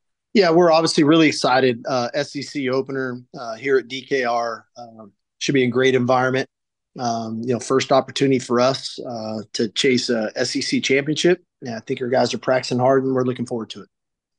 Head coach Steve Sarkisian spoke on the team’s SEC debut and how they are approaching the game.